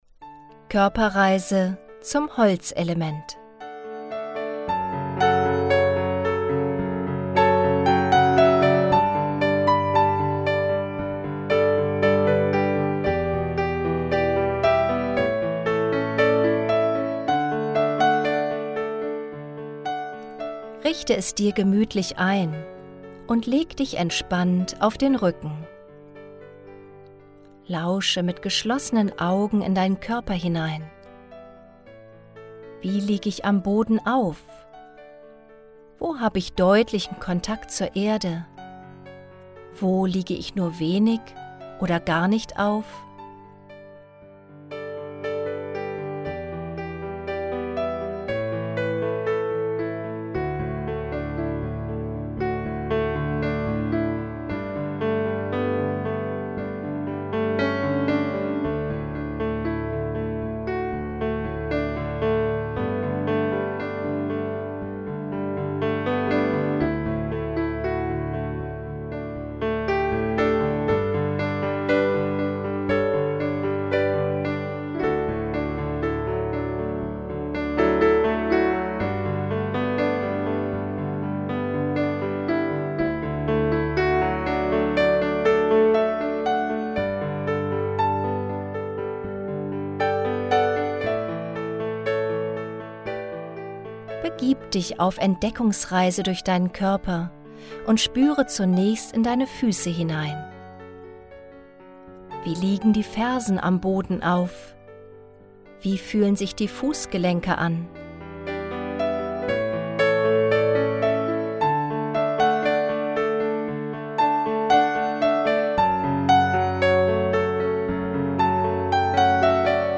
Dabei wechseln sich die geführten Anleitungen mit Phasen des Nachspürens ab. Die wohltuende, dezente Musikuntermalung trägt zusätzlich zur tiefen Entspannung bei.